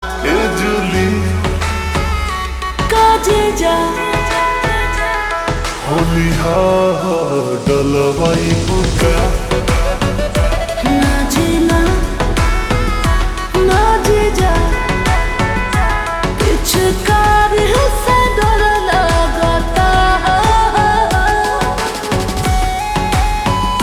Bhojpuri track